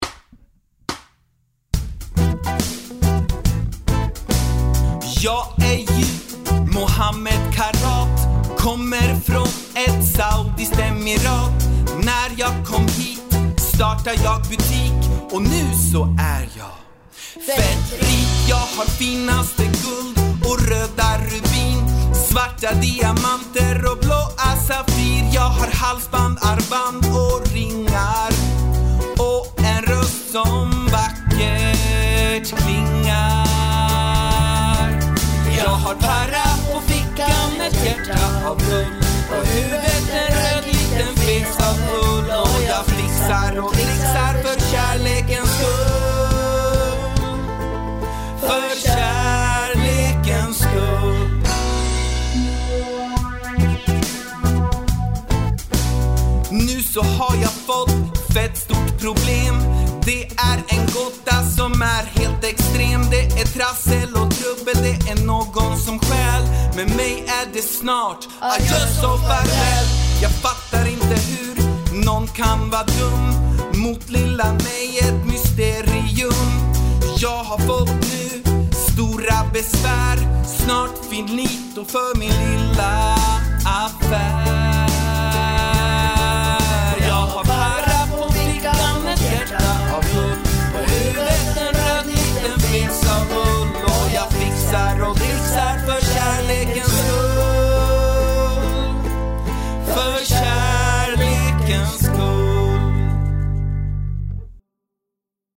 Sångversion